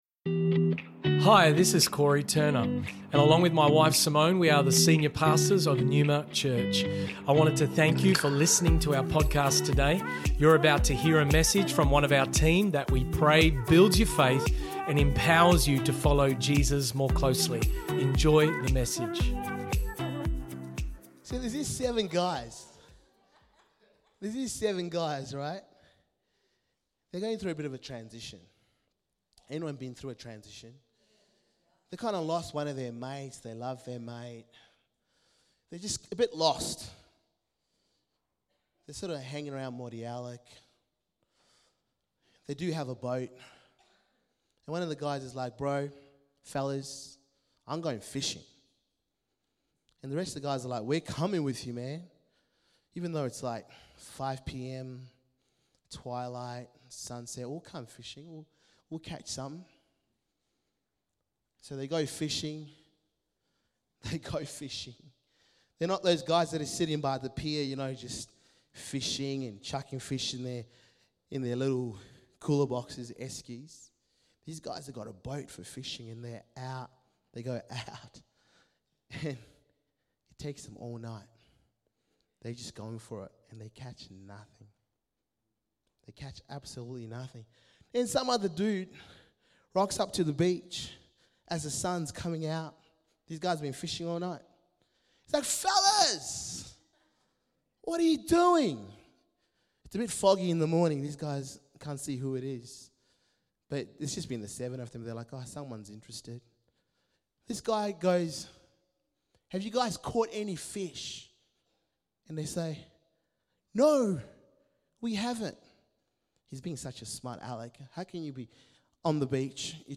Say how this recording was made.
Neuma Church Melbourne South Originally Recorded at the 10am Service on Sunday 1st October 2023.&nbsp